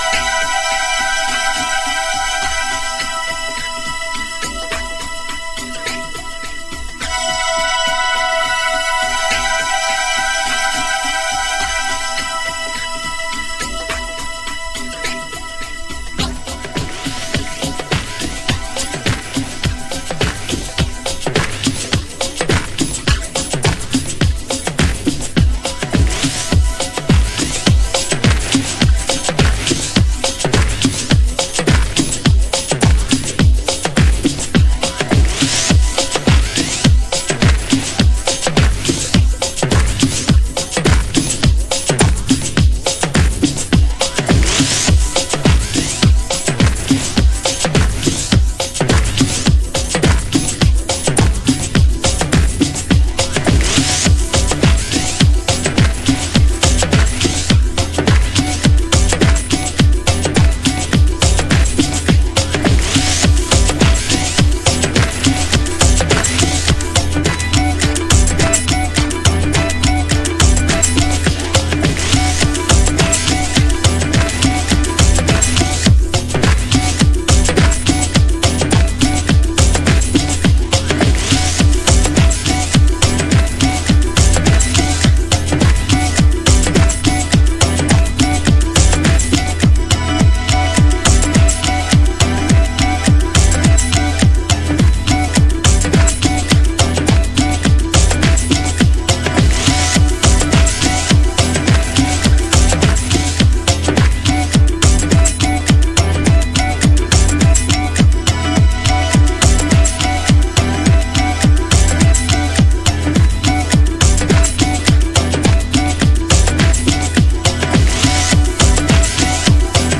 独創的な凹凸、奥深いイーヴンキックの世界。
House / Techno